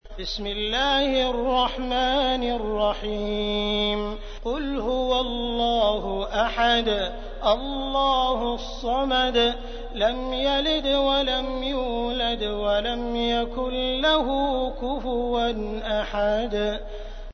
تحميل : 112. سورة الإخلاص / القارئ عبد الرحمن السديس / القرآن الكريم / موقع يا حسين